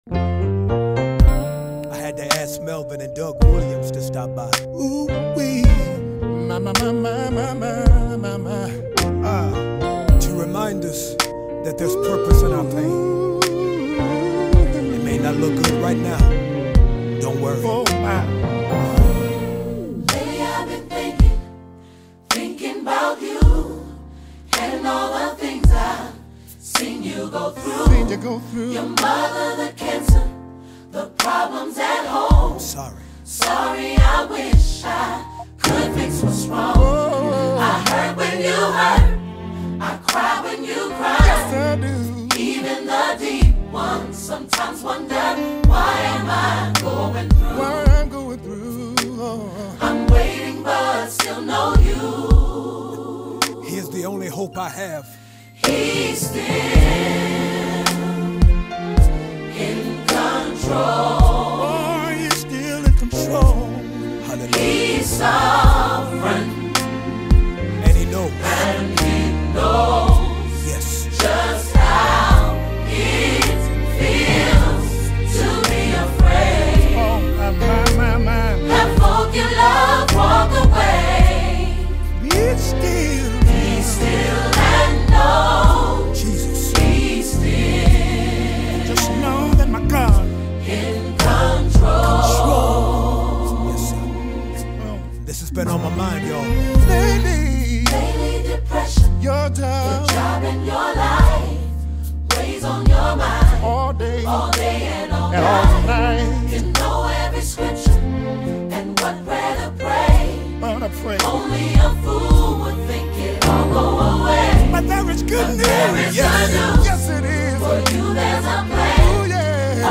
Genre:Gospel